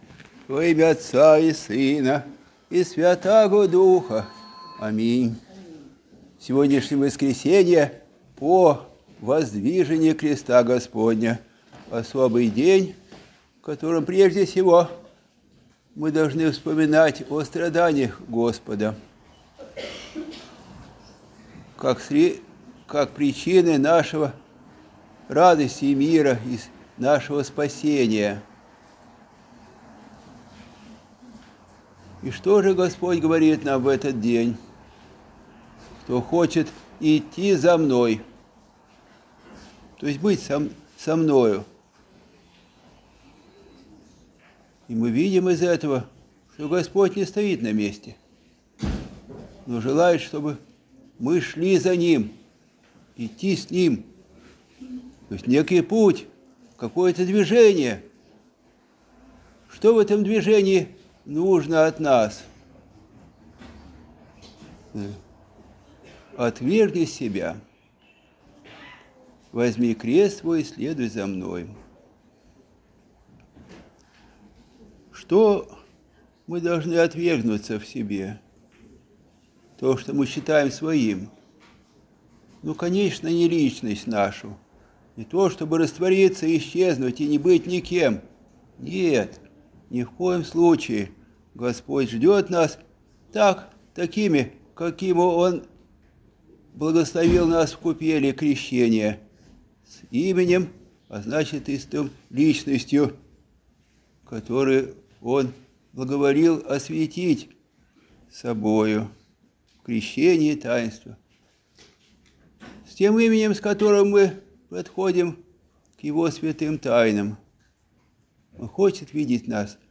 Проповедь прот.